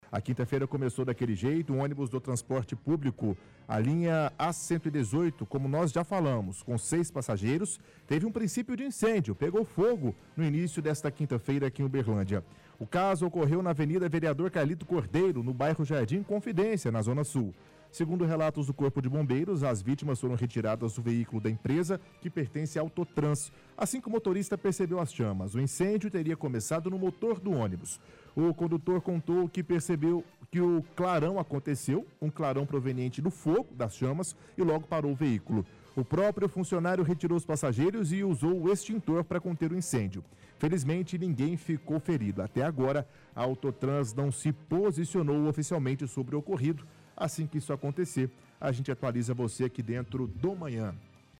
– Leitura de reportagem do site do Diário de Uberlândia informando que ônibus do transporte coletivo pegou em Uberlândia.